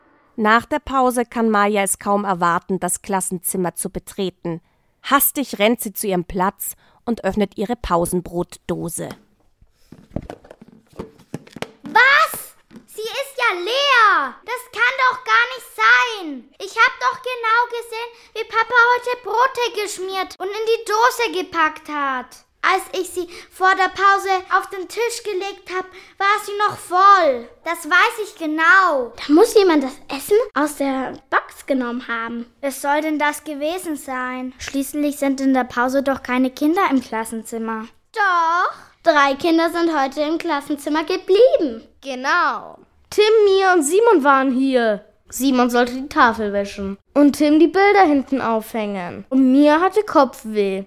Kriminell gut hören, Klasse 1/2 Fesselnde Hörspielkrimis mit differenzierten Aufgaben zur Förderung der Hörkompetenz Sabine Reichel (Autor) Buch | Softcover 80 Seiten | Ausstattung: CD-ROM (Software) 2024 | 5.